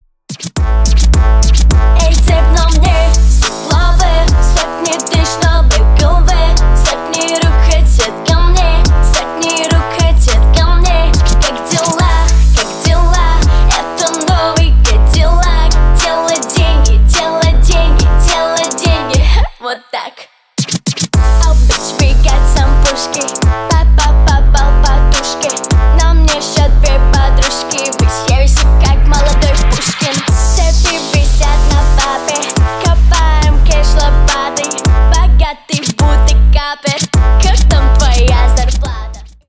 Рэп и Хип Хоп
кавер